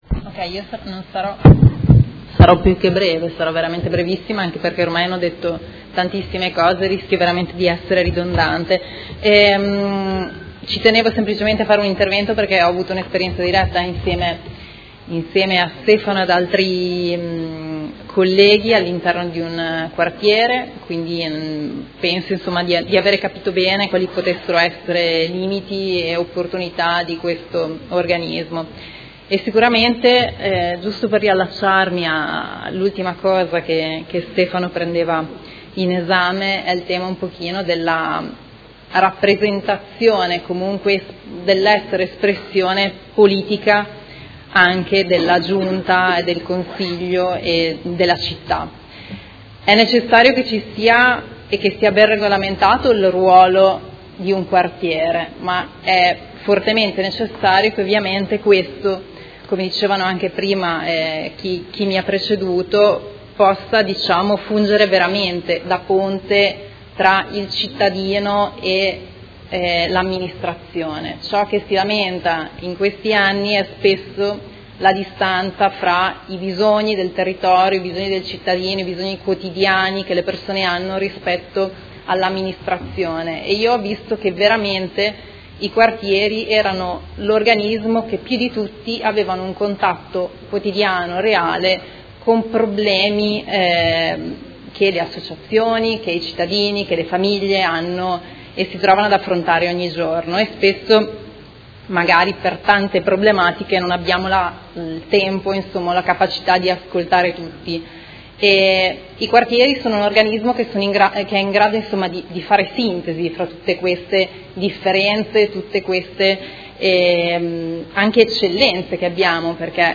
Seduta del 26/09/2019. Dibattito su proposta di deliberazione: Costituzione dei Consigli di Quartiere, e Ordine del Giorno Prot. Gen n. 282643
Audio Consiglio Comunale